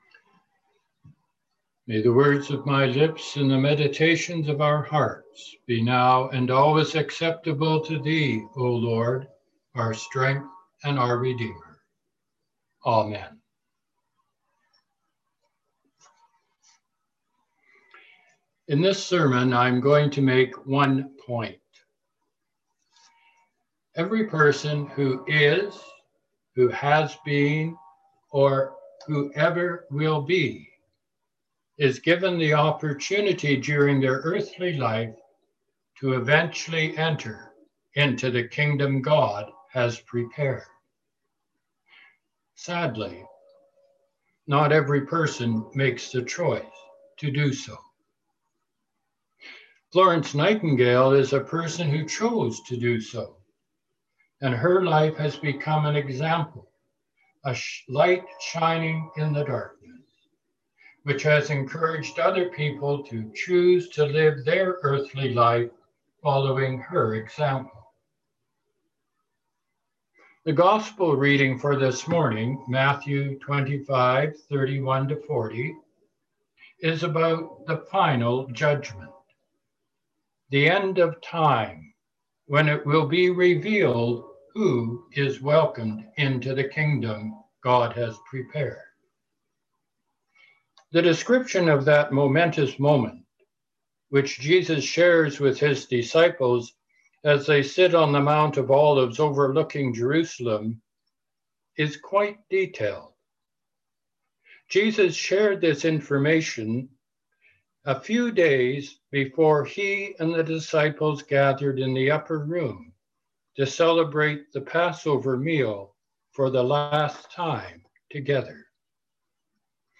Sermons | St. George's Anglican Church